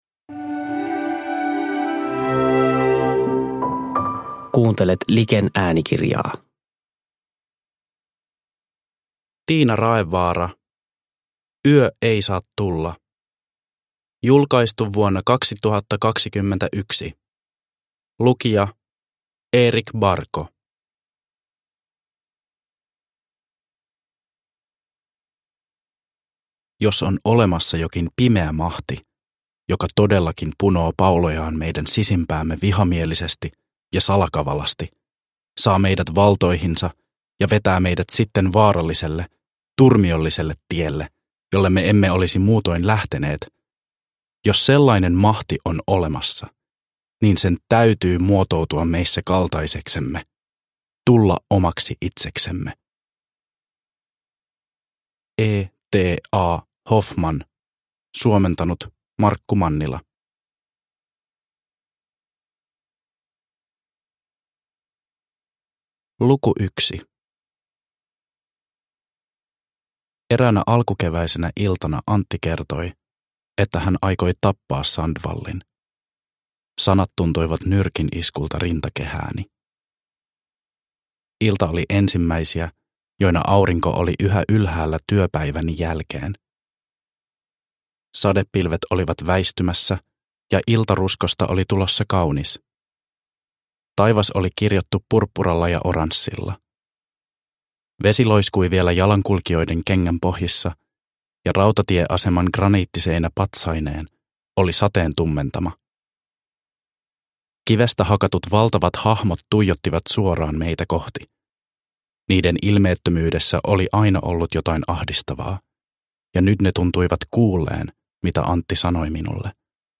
Yö ei saa tulla – Ljudbok – Laddas ner